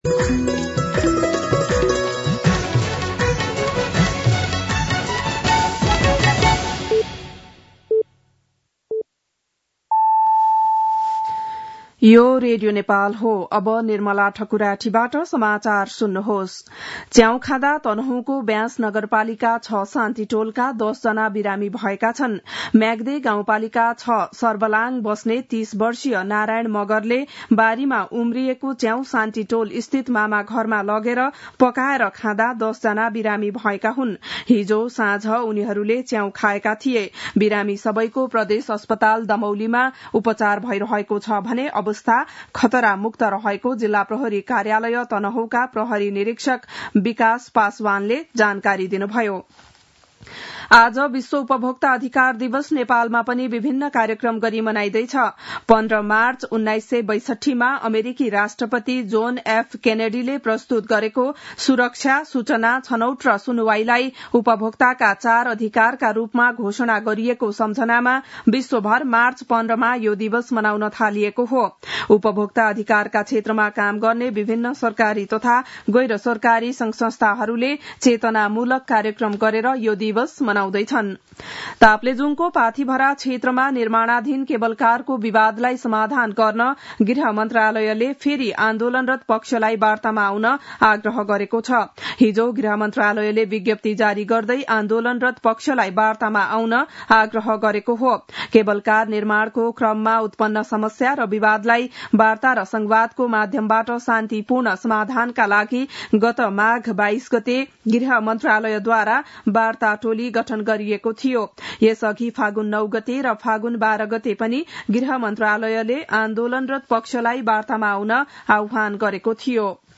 बिहान ११ बजेको नेपाली समाचार : २ चैत , २०८१
11-am-Nepali-News.mp3